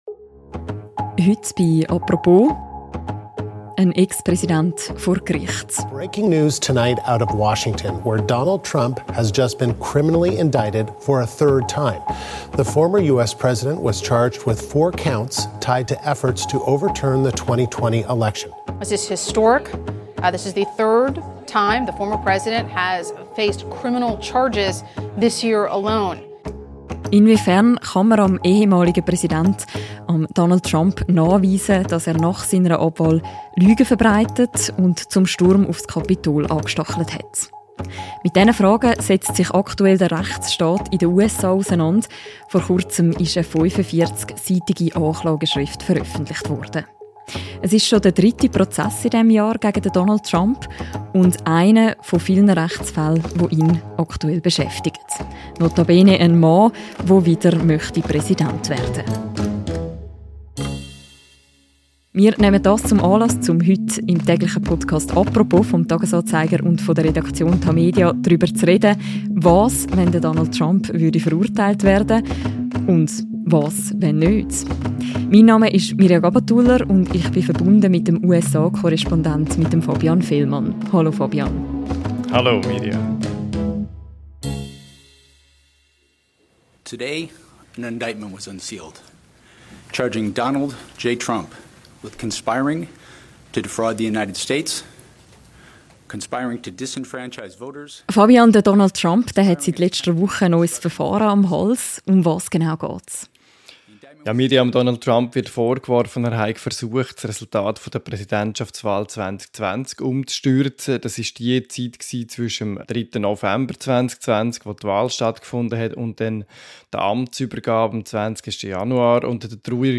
Diese und weitere Fragen beantwortet USA-Korrespondent